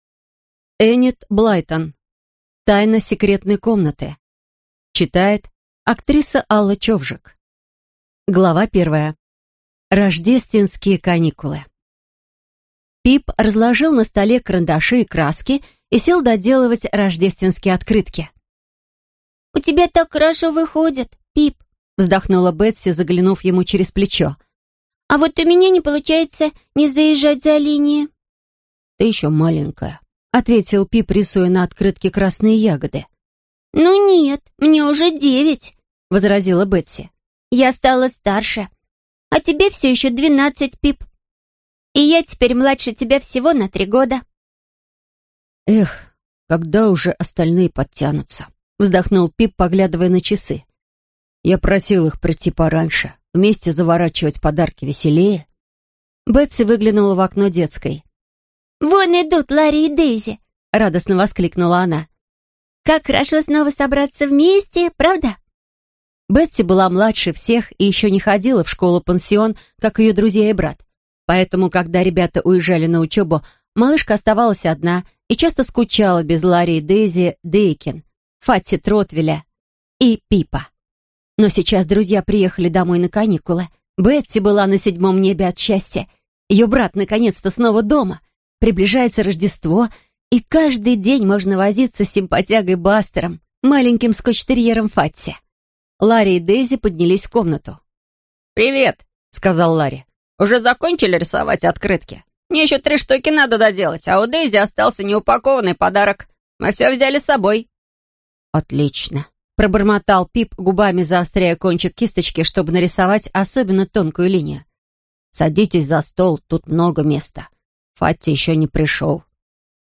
Аудиокнига Тайна секретной комнаты | Библиотека аудиокниг